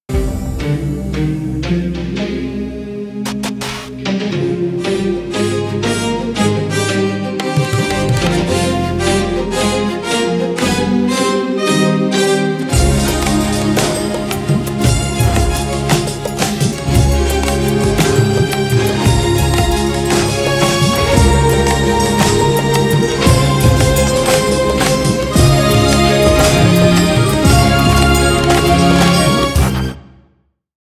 Hybrid ElectronicUpliftingPowerful